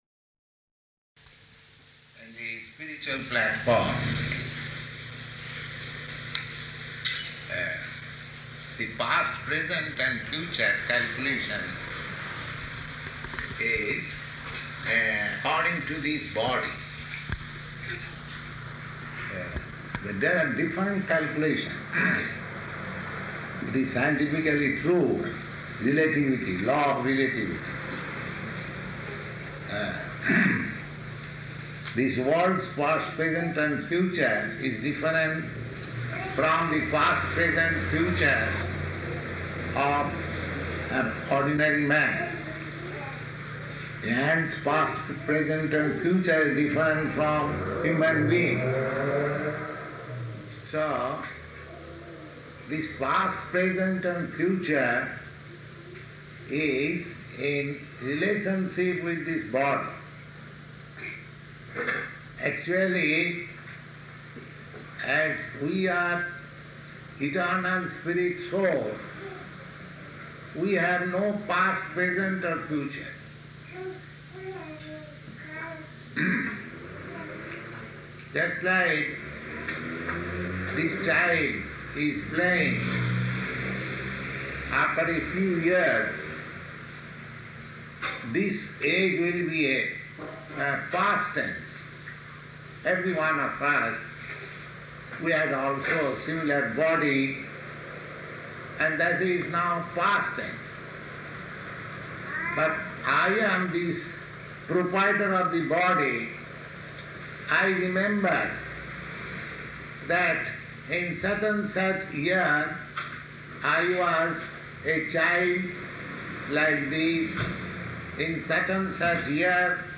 Lecture
Lecture --:-- --:-- Type: Lectures and Addresses Dated: July 11th 1971 Location: Los Angeles Audio file: 710711LE-LOS_ANGELES.mp3 Prabhupāda: ...and the spiritual platform.